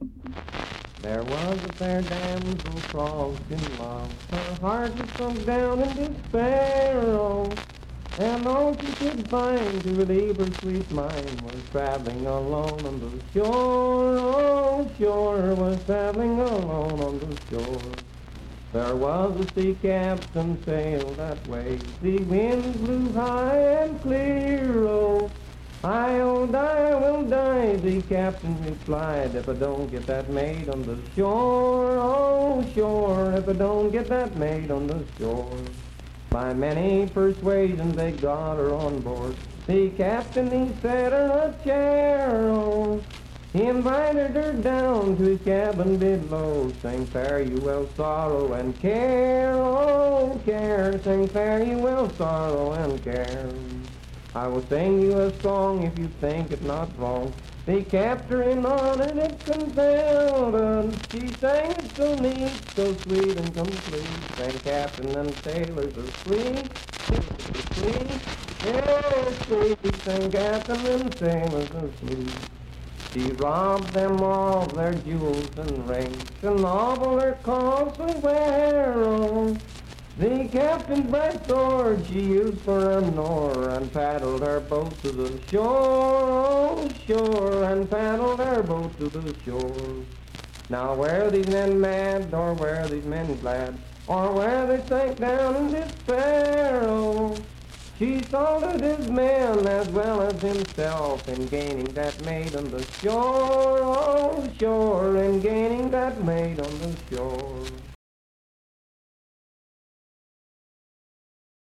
Unaccompanied vocal performance
Verse-refrain 6(5w/R).
Voice (sung)
Spencer (W. Va.), Roane County (W. Va.)